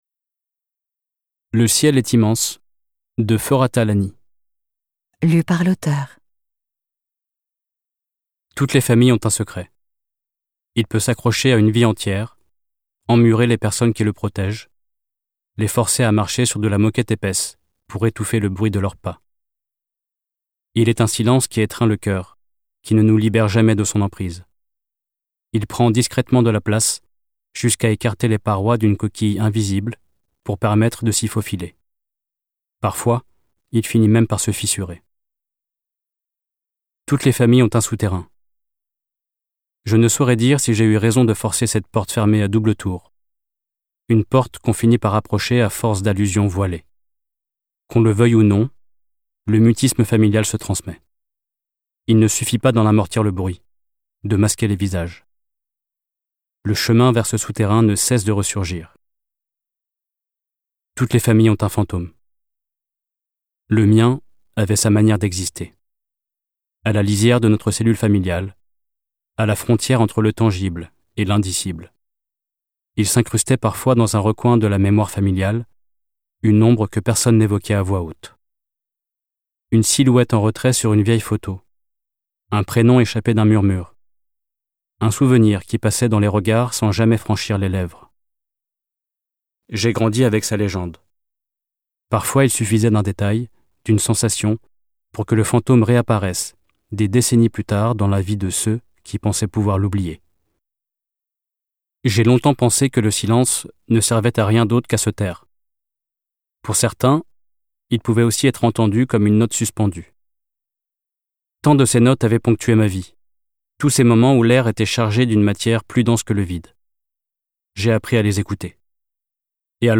Diffusion distribution ebook et livre audio - Catalogue livres numériques
Interprétation humaine Durée : 05H06 20 , 95 € Ce livre est accessible aux handicaps Voir les informations d'accessibilité